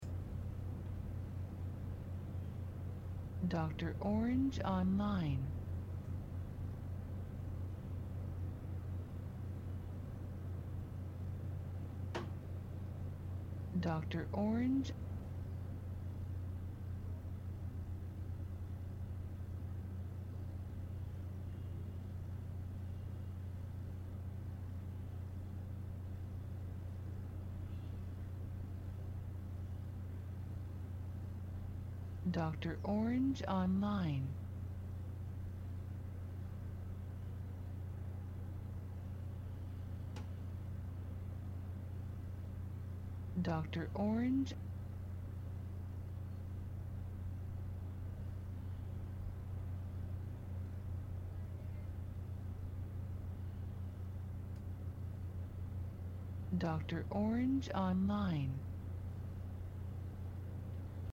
Ambiente Mono Interior Habitación
INTERIOR AMBIENTE GENERICO, HABITACIÓN, SONIDO PERMANENTE.
GRATIS-AMBIENTE-HABITACION-96KHZ.mp3